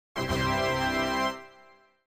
SOUND EFFECT TADA